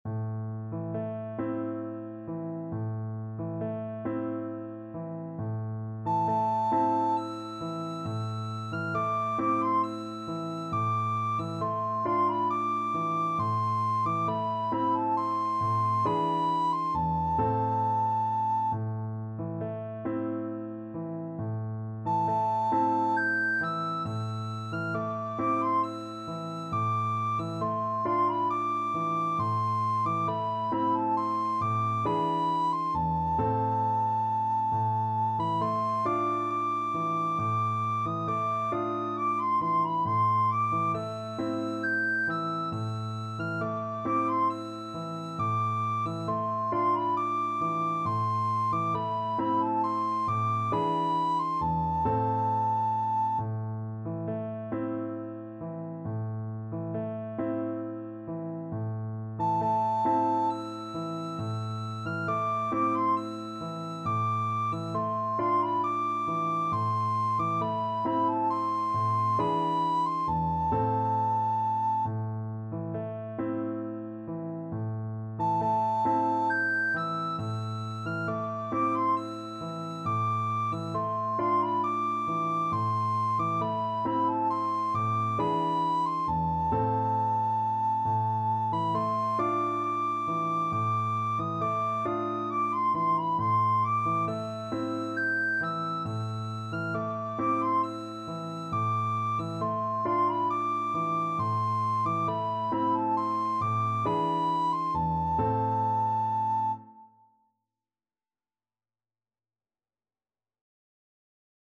Recorder
A minor (Sounding Pitch) (View more A minor Music for Recorder )
Gently rocking .=c.45
Turkish